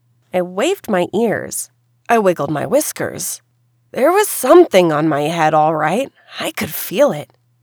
Mic sounds like a tin can with a bit of bass
To me, it sounds a bit tin canny, bassy, or like I’m in the toilet. It is also a lot more sensitive right now and picking up a higher noise floor (was about -71, now is about -60).
Equipment: Mic- HyperX Quadcast (usb) ; Laptop- Asus Vivobook - Running on Windows 11
I had to put it on one file in order to post. 1st is the issue I had yesterday/this morning. 2nd is what it currently sounds like. 3rd is what it originally sounded like.